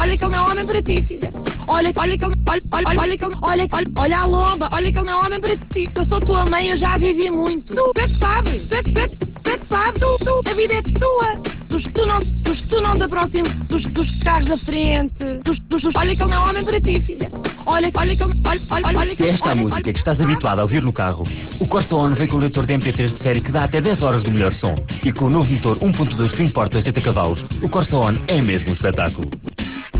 ...o novo Opel Corsa vem com leitor de MP3 de série? Esta divertida campanha estreou no dia 19 de Outubro nas rádios RFM e RC (